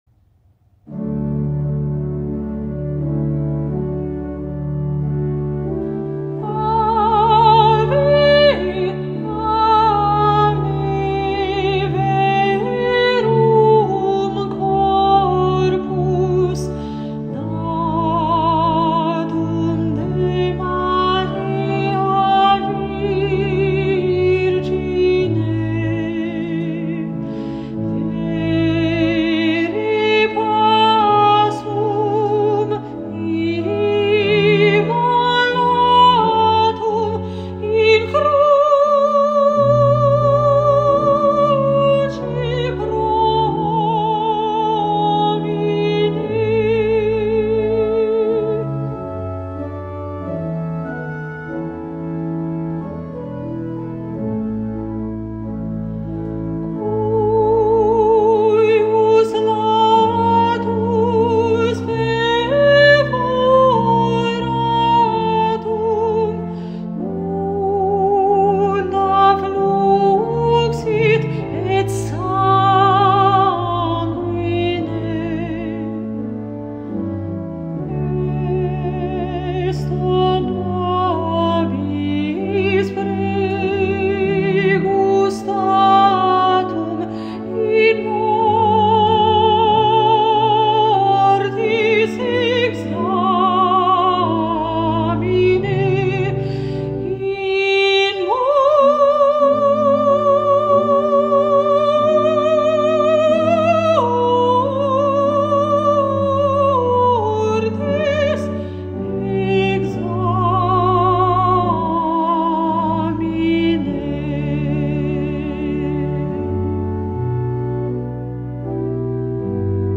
MP3 versions chantées
Soprano
Ave Verum Corpus Mozart Soprano Practice Mp 3